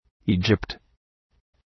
Προφορά
{‘i:dʒıpt}